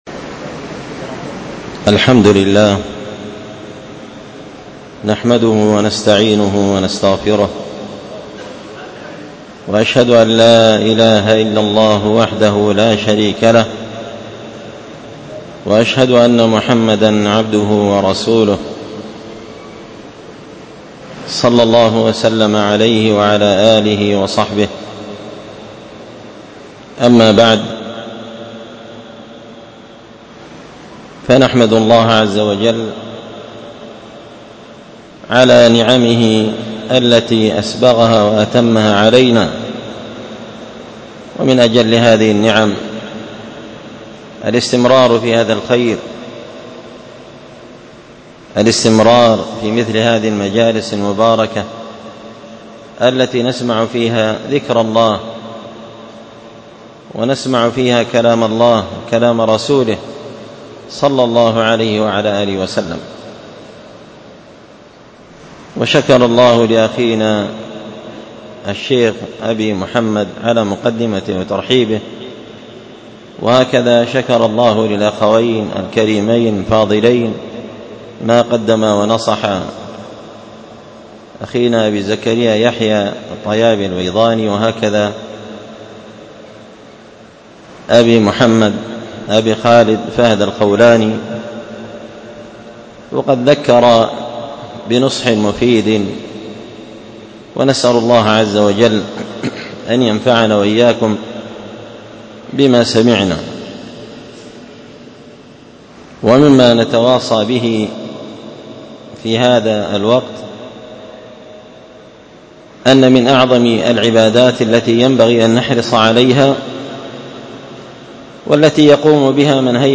كلمة بعنوان: